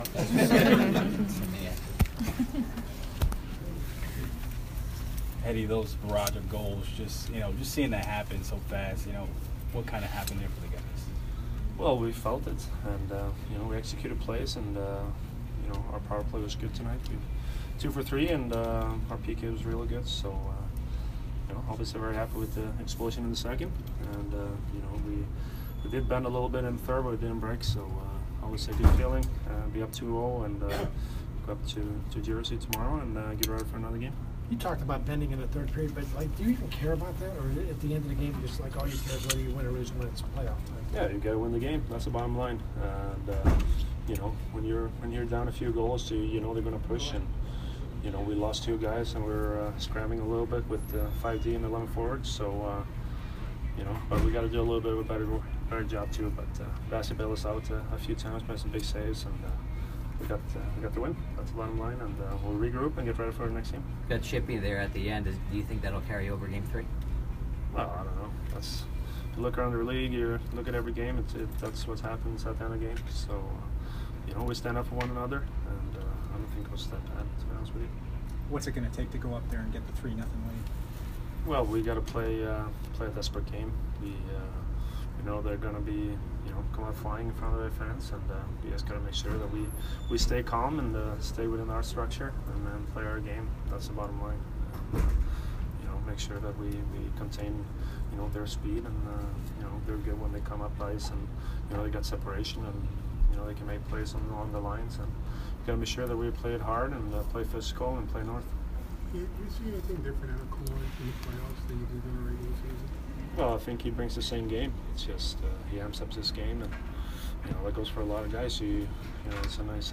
Victor Hedman post-game 4/14